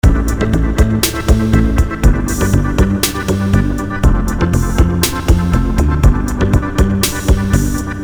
Sonido Loop 7 de 12
electro14.mp3